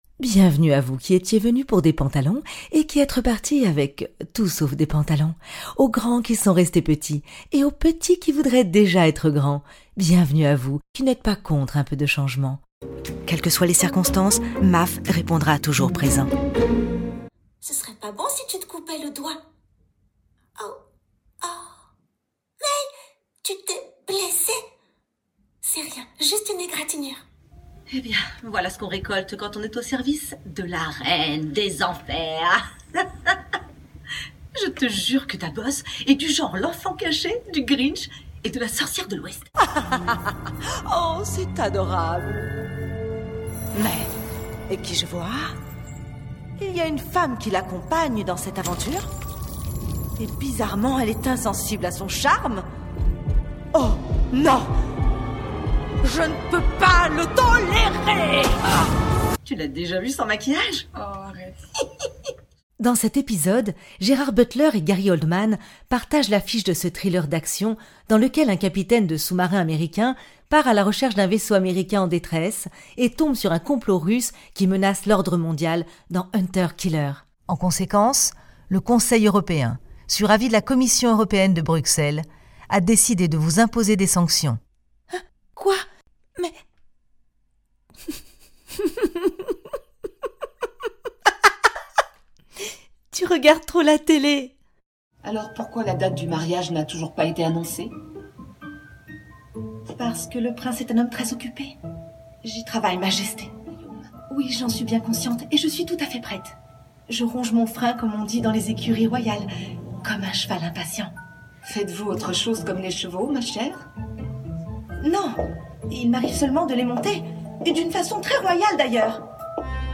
Voix off
Bandes-son
- Soprano